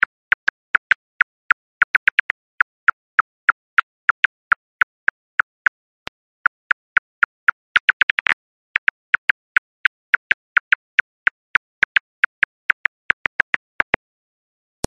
Gümüş tüylü yarasanın ekolokasyon sesi.
Yarasalar geceleri avlanırken insan kulağının algılayamayacağı tiz tıkırtı ve gıcırtı sesleri çıkarır.
gumus-tuylu-yarasa-ekolokasyon-sesi.mp3